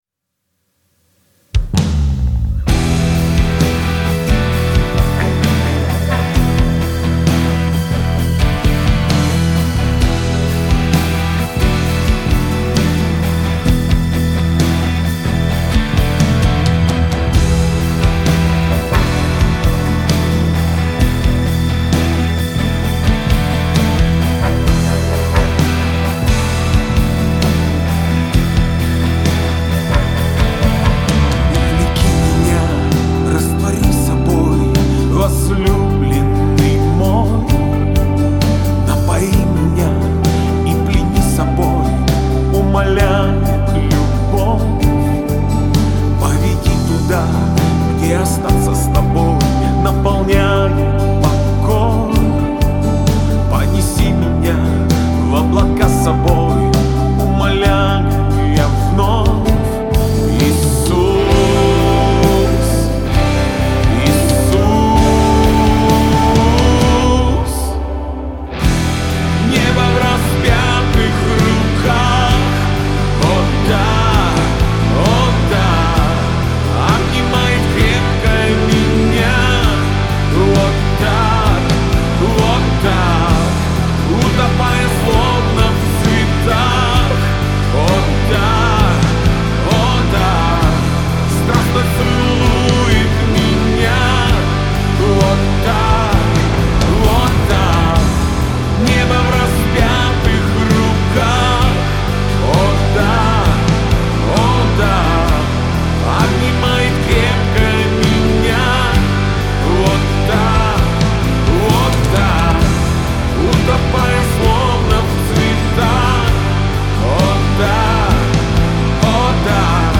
1878 просмотров 1074 прослушивания 85 скачиваний BPM: 131